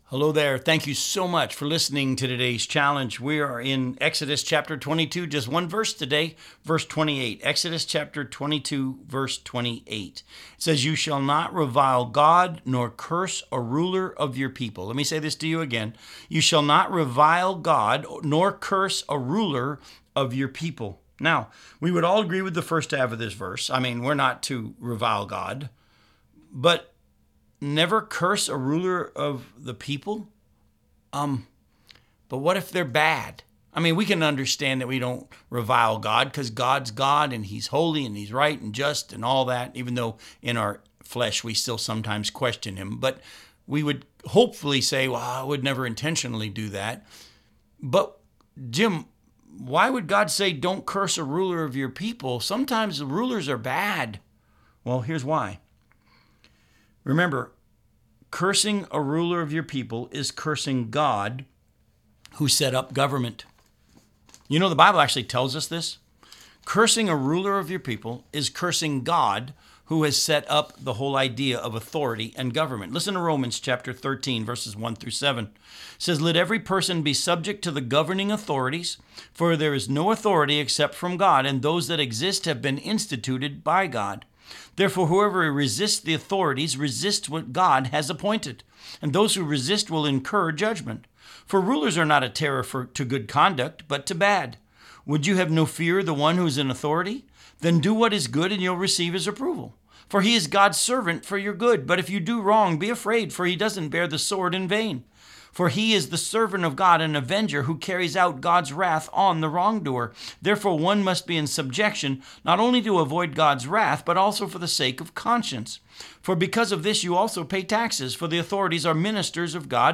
Challenge for Today Radio Program